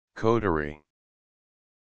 Audio Pronunciation of Coterie